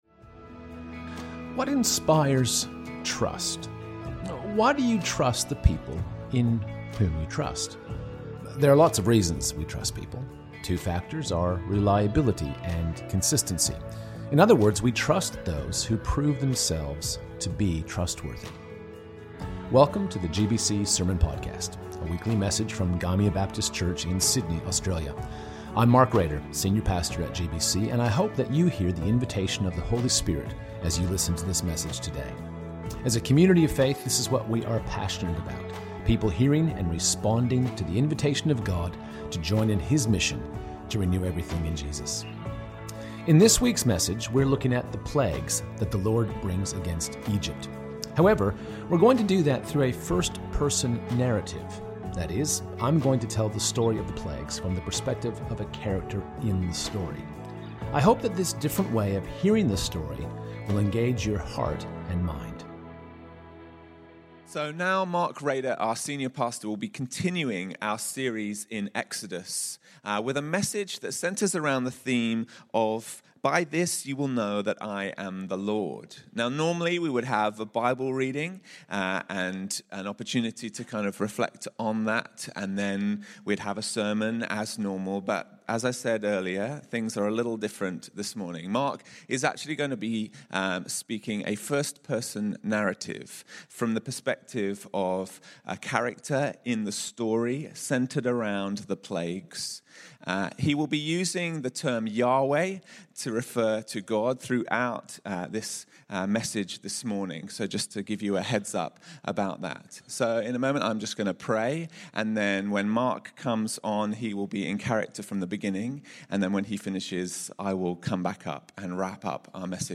GBC | Sermons | Gymea Baptist Church
This week we look at the plagues of Egypt but from a slightly different perspective; that of a character in the story. This first-person narrative is a creative, engaging way to consider a sometimes familiar story.